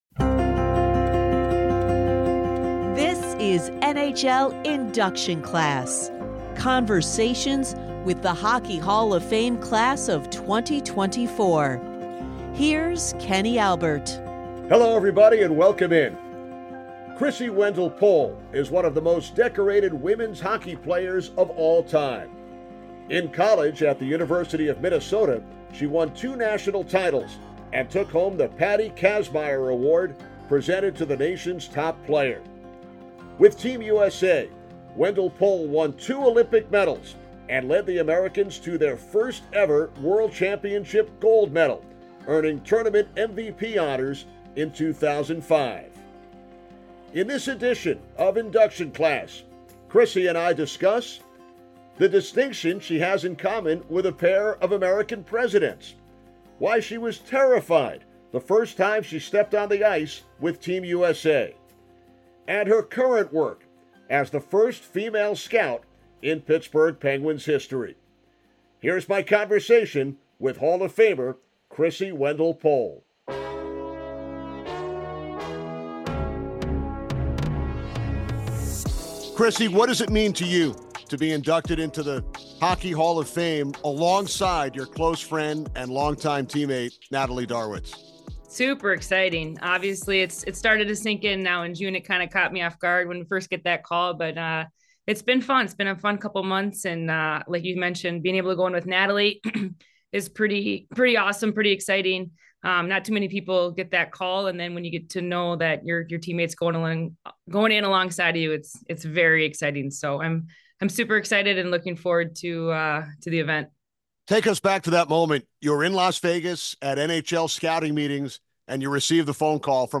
Headliner Embed Embed code See more options Share Facebook X Subscribe Women’s hockey legend Krissy Wendell-Pohl chats with Kenny Albert about her historic appearance in the Little League World Series, the unique distinction she shares with a pair of US Presidents, why she was “terrified” the first time she stepped on the ice with Team USA, and her current work as an amateur scout with the Pittsburgh Penguins.